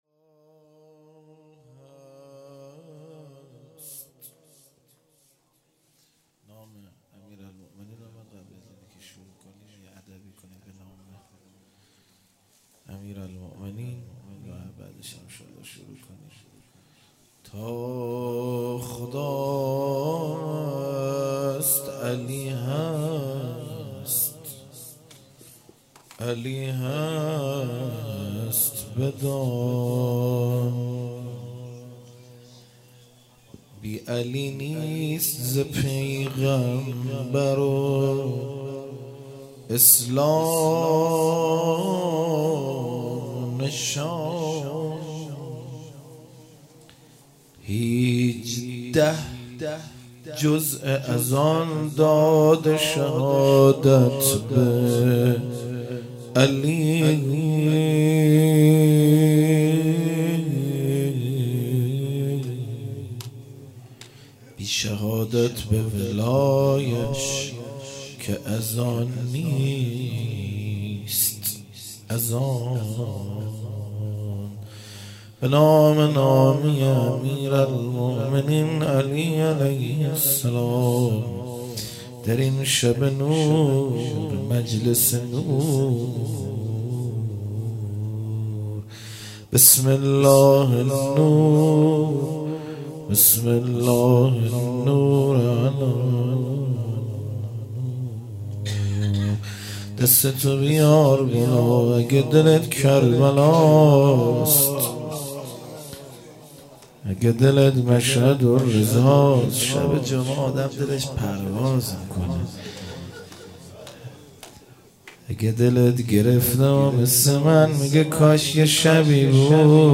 روضه